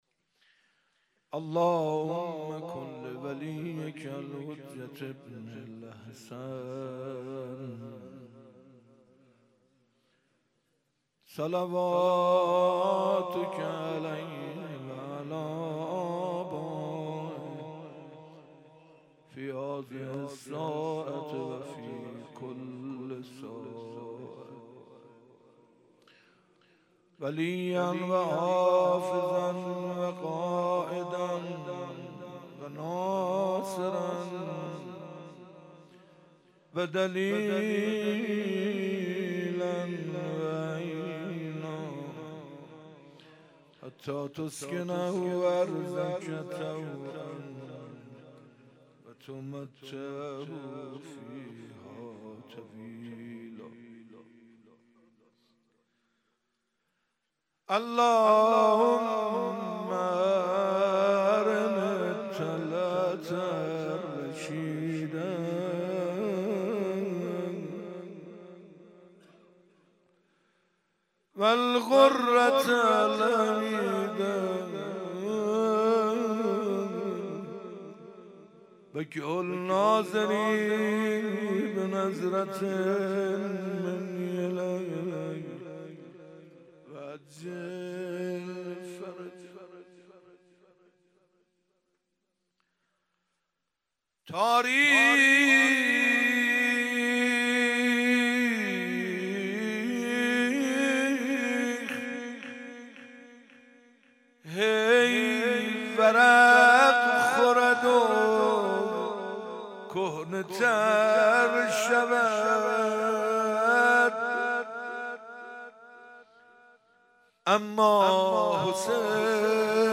29 محرم 97 - بیت الرضوان - غزل - تاریخ هی ورق خورد و کهنه تر شود
محرم 97